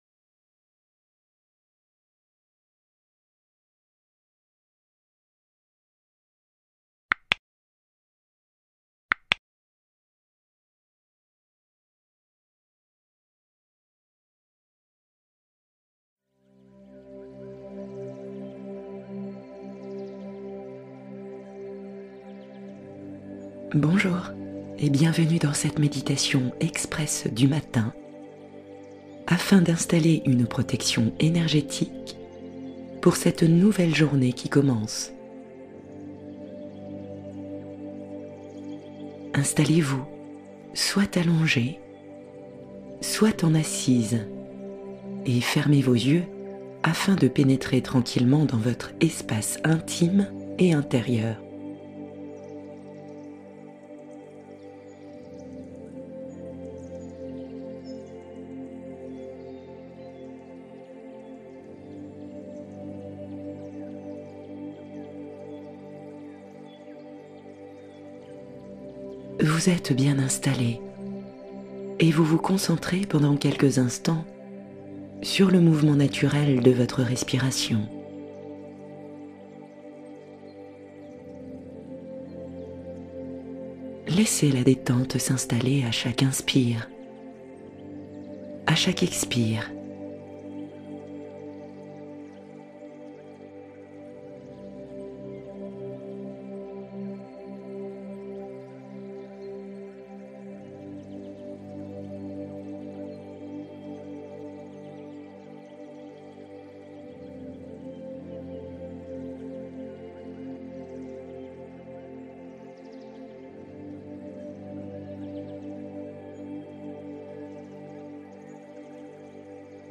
Détente du dos guidée : libération progressive des tensions profondes